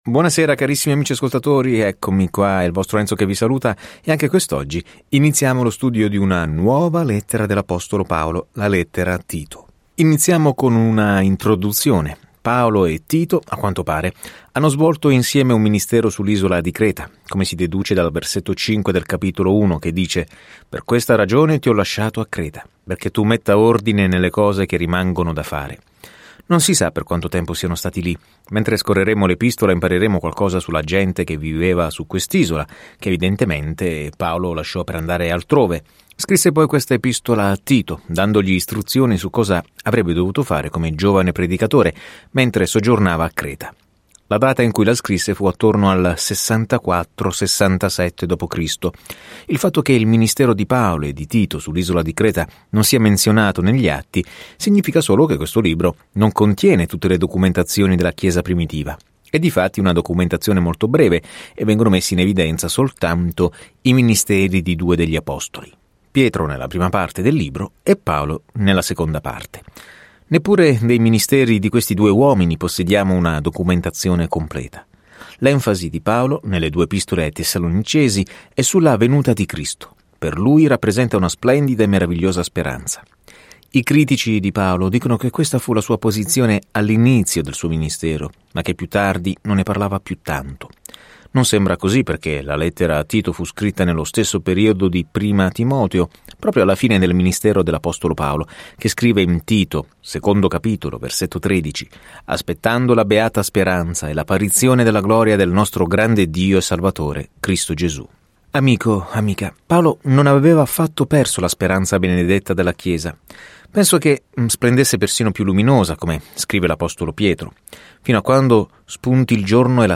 Scrittura Lettera a Tito 1:1-4 Inizia questo Piano Giorno 2 Riguardo questo Piano Questa lettera è indirizzata a un giovane pastore che elenca un "chi è chi in chiesa" controculturale e descrive come tutti i tipi di persone possono servirsi e amarsi a vicenda. Viaggia ogni giorno attraverso Tito mentre ascolti lo studio audio e leggi versetti selezionati della parola di Dio.